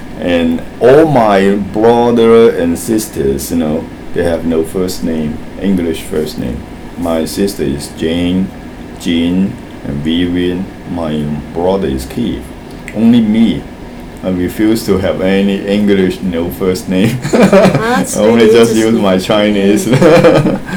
S1 = Taiwanese female S2 = Hong Kong male Context: They are talking about S2's name.
To understand this, we need to note S2's habit of saying you know very regularly, often inserting two or three tokens of this phrase into a single utterance.